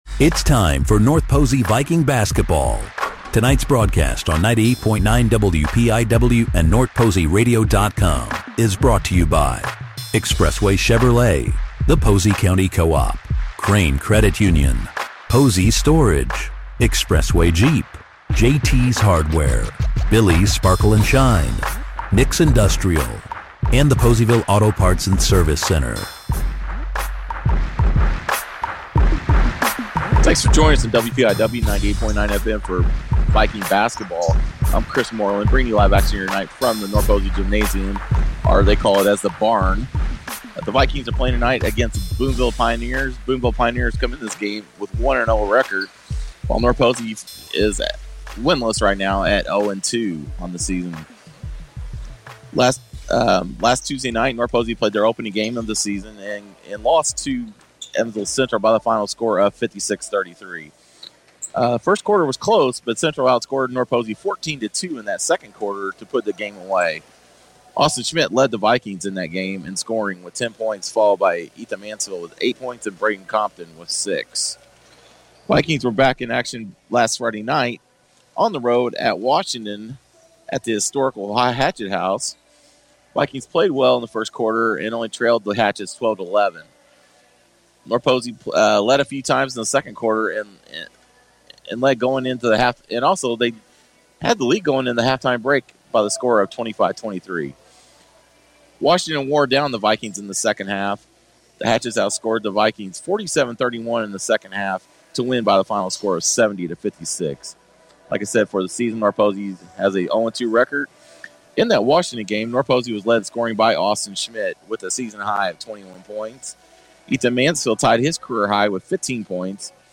Listen to the game archive below.